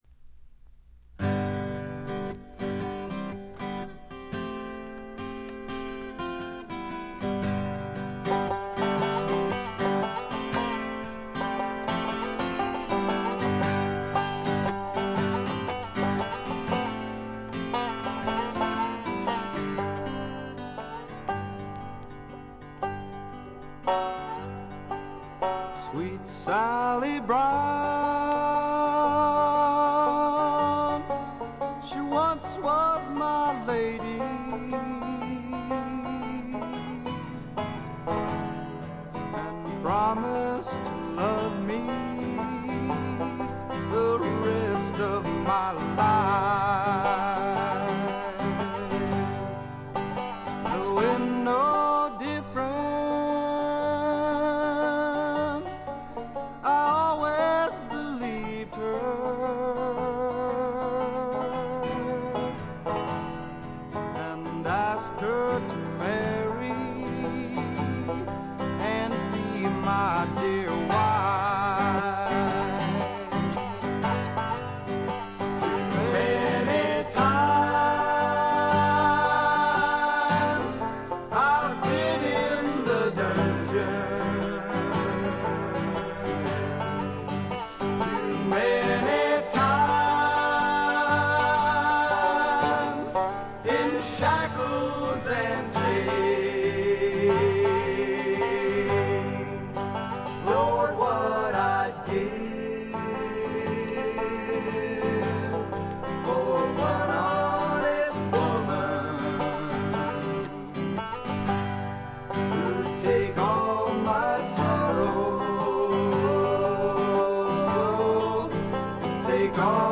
個人的にはジャケットの写真にあるようにフルアコ2台のエレクトリック・ギターの音が妙にツボに来ます。
試聴曲は現品からの取り込み音源です。
Guitar, Banjo, Vocals